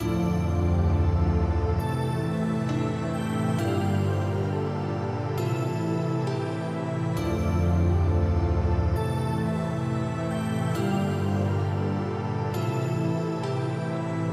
标签： 134 bpm Trap Loops Piano Loops 2.41 MB wav Key : Unknown
声道立体声